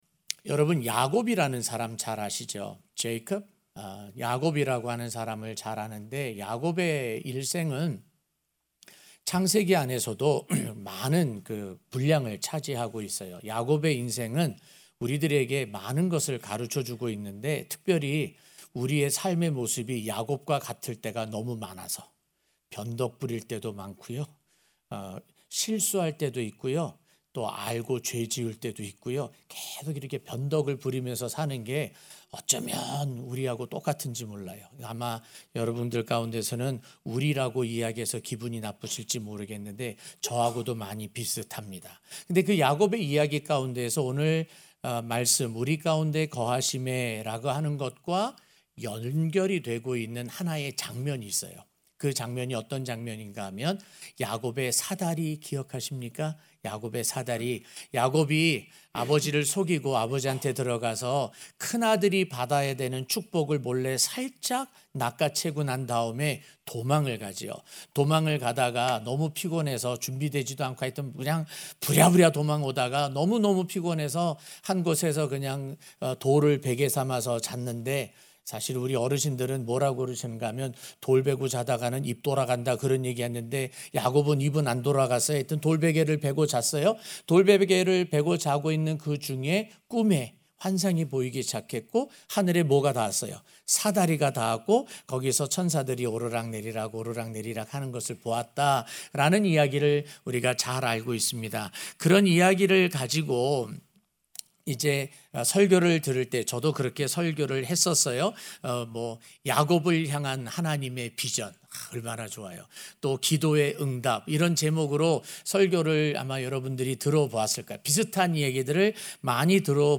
우리 가운데 거하시매(성탄절 설교)